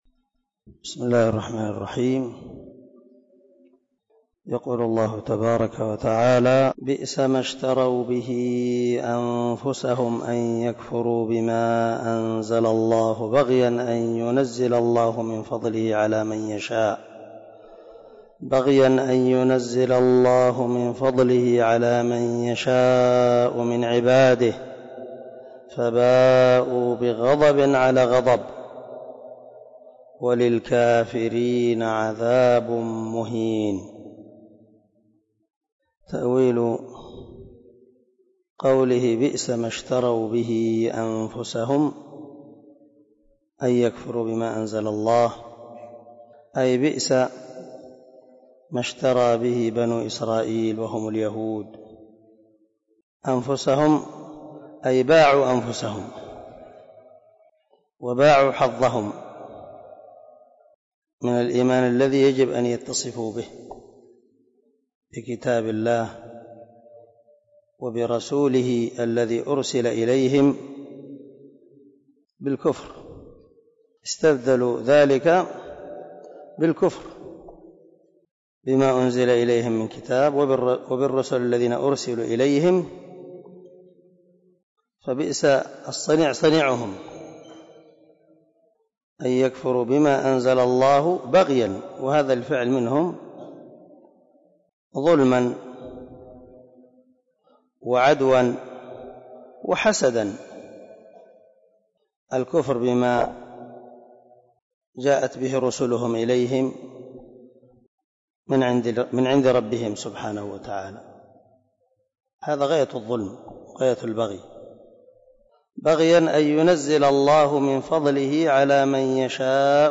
039لدرس 29 تفسير آية ( 90 – 93 ) من سورة البقرة من تفسير القران الكريم مع قراءة لتفسير السعدي